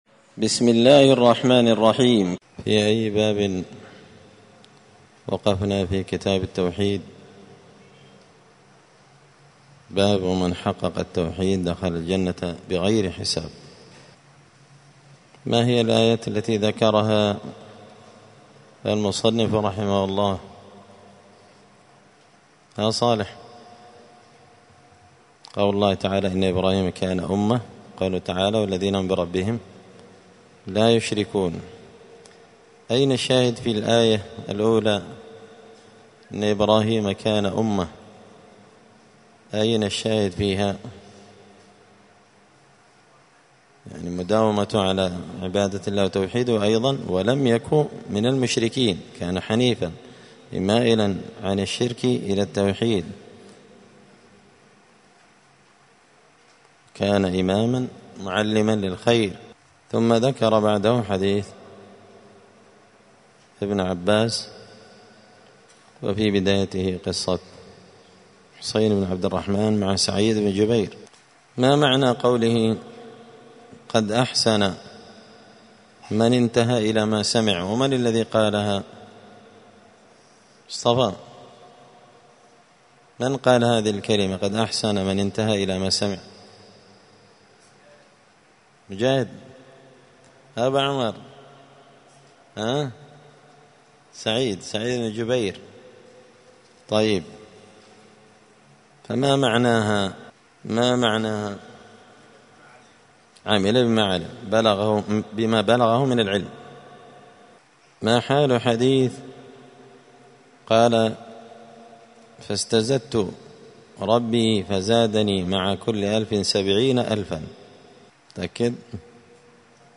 دار الحديث السلفية بمسجد الفرقان بقشن المهرة اليمن
*الدرس الخامس عشر (15) {تابع للباب الثالث باب من حقق التوحيد دخل الجنة بغير حساب…}*